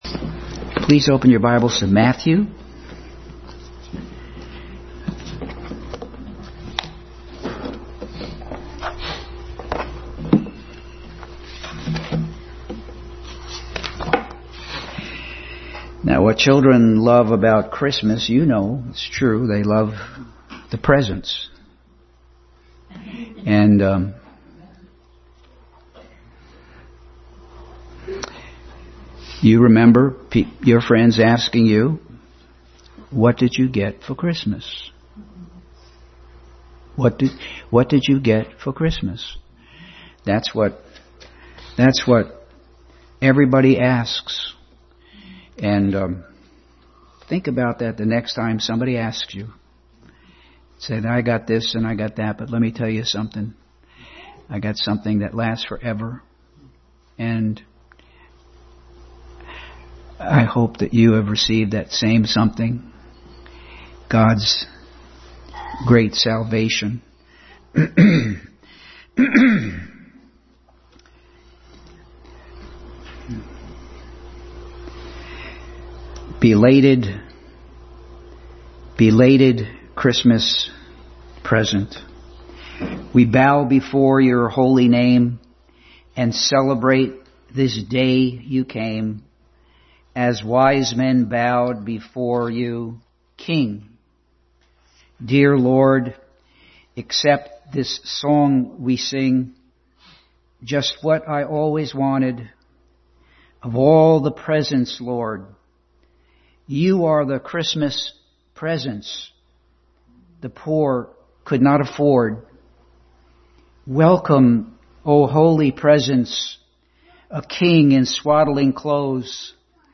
Passage: Matthew 1:1-17, 2:1-12, Psalm 19 Service Type: Family Bible Hour